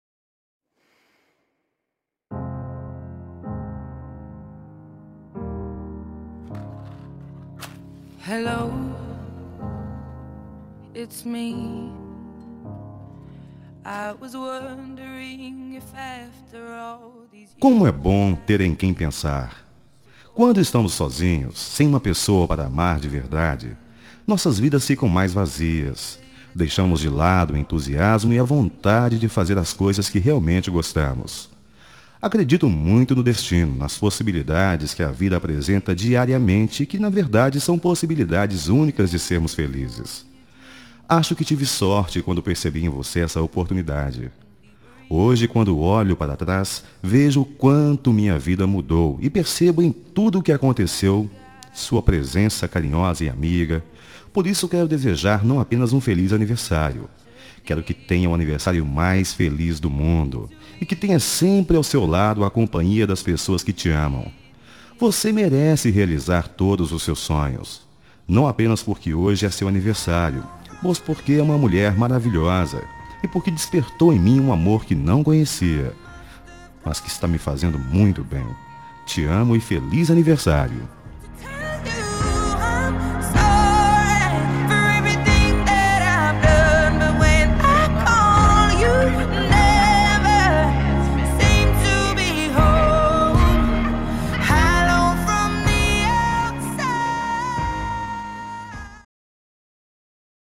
Telemensagem de Aniversário Romântico – Voz Masculino – Cód: 1054